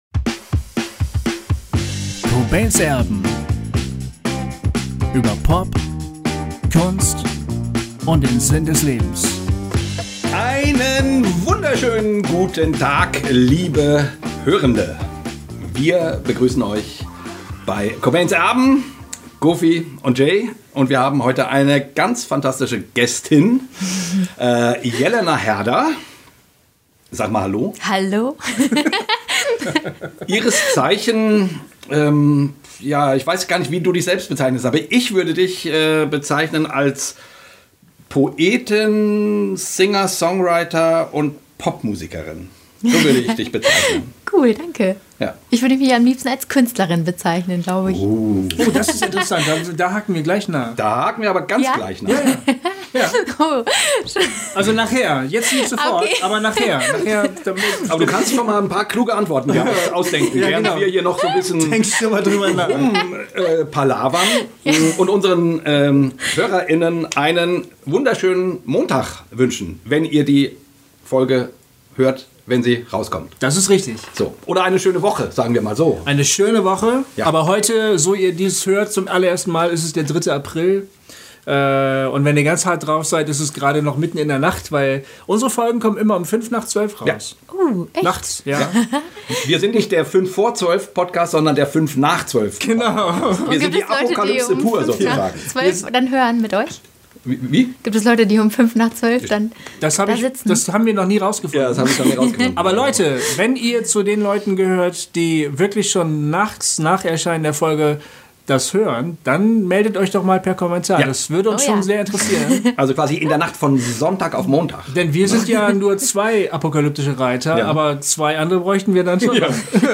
Wir haben dieses Gespräch mit ihr sehr genossen.